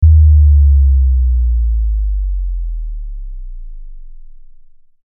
Звуки басов
Звук баса с эффектом затухания